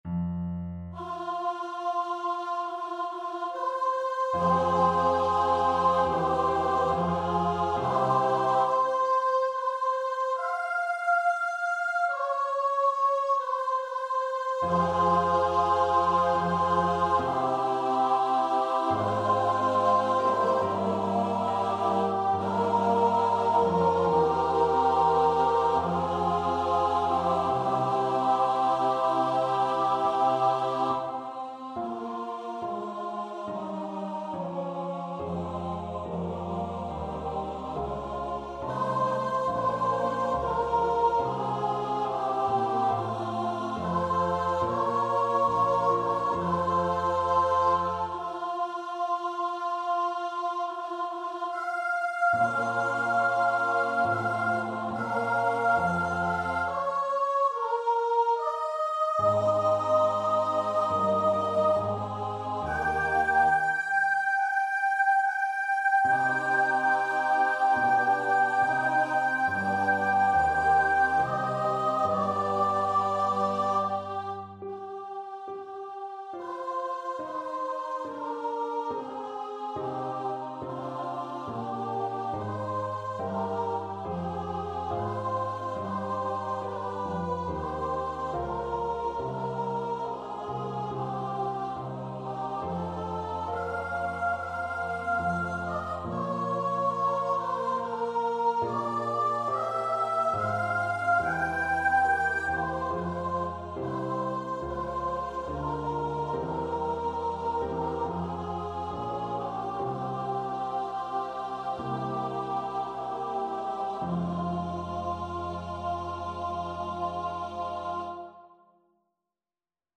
Free Sheet music for Choir
Classical (View more Classical Choir Music)